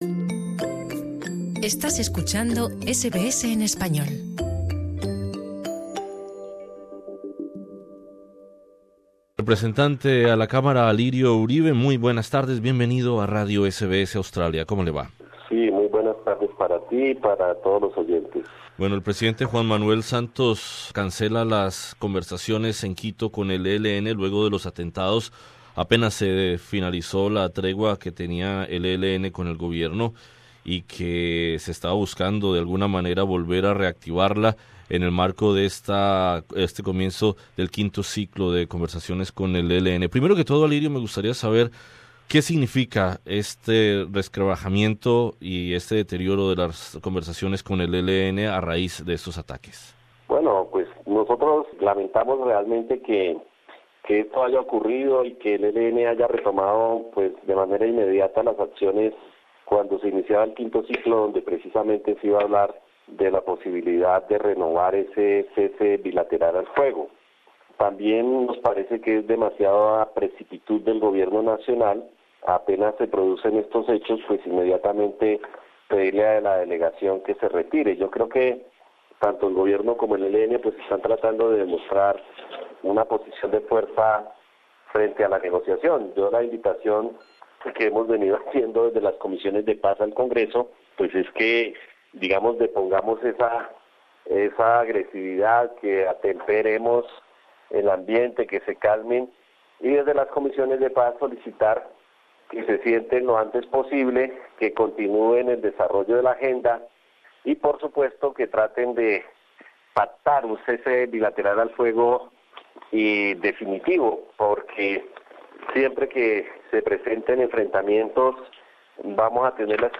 Entrevista con el representante a la Cámara Alirio Uribe.